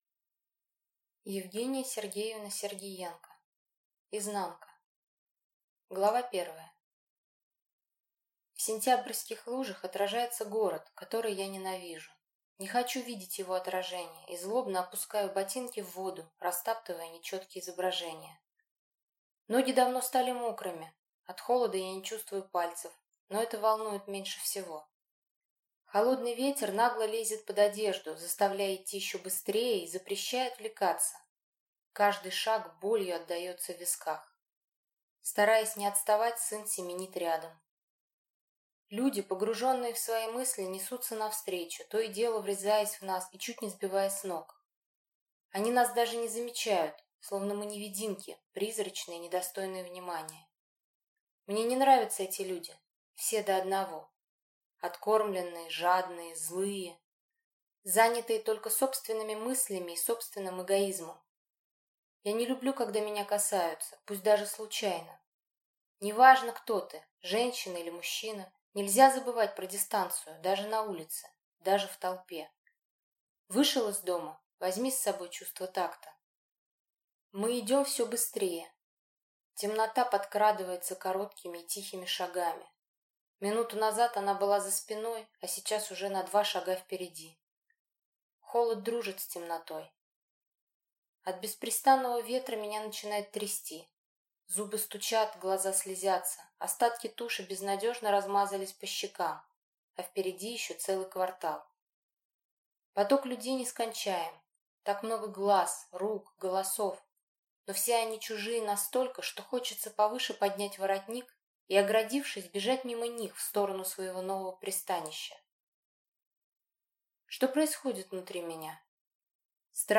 Аудиокнига Изнанка | Библиотека аудиокниг